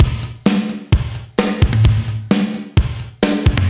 Index of /musicradar/80s-heat-samples/130bpm
AM_GateDrums_130-01.wav